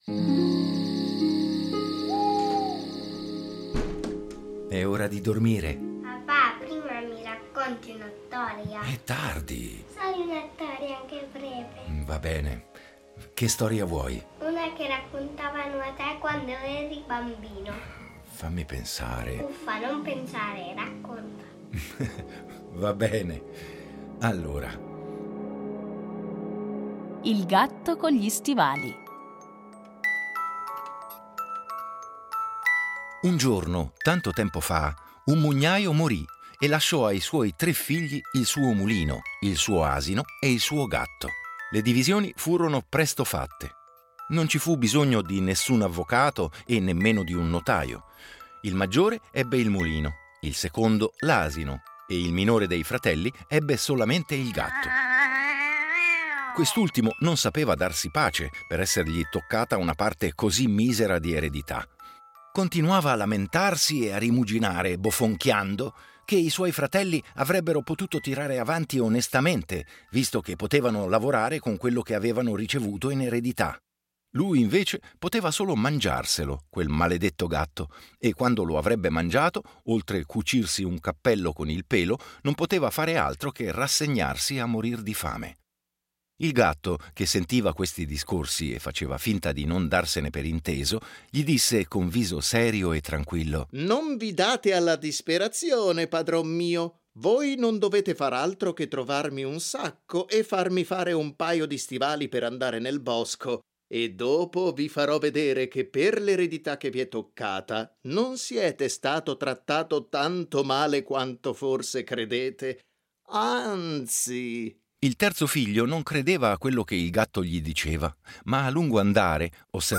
Fiabe